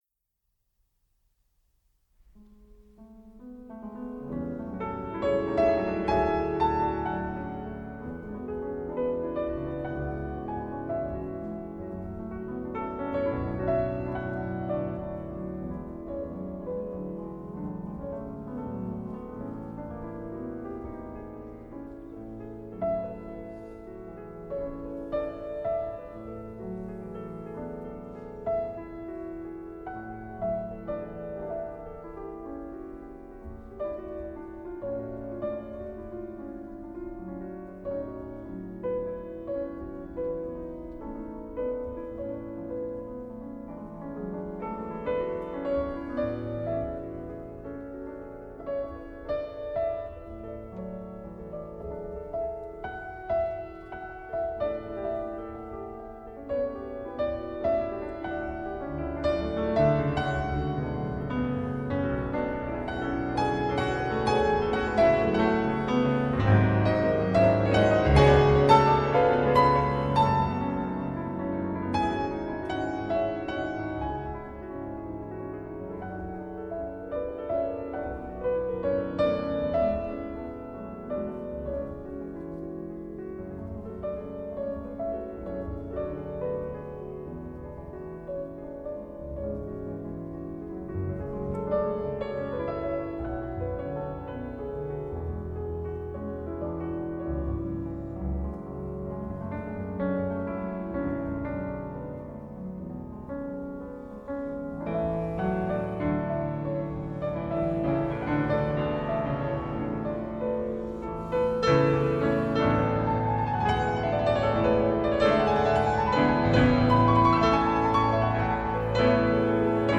آلبوم کلاسیکال